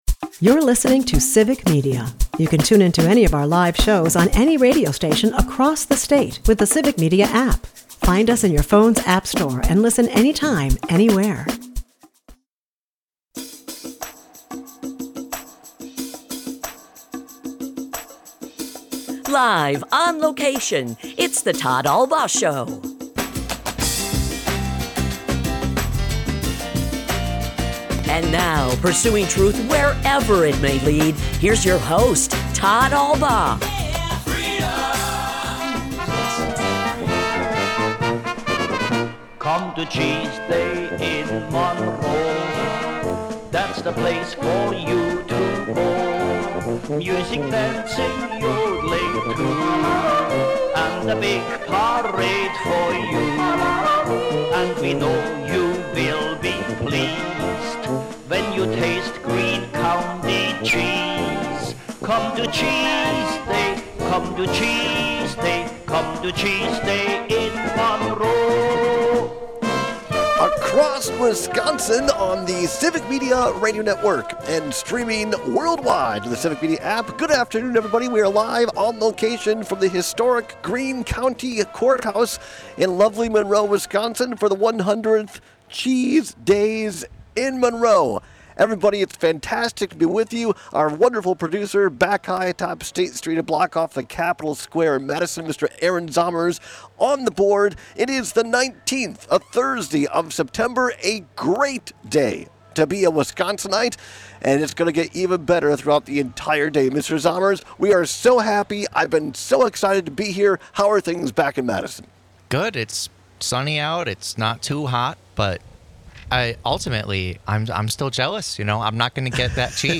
LIVE From Cheese Days In Monroe! (Hour 1) - Civic Media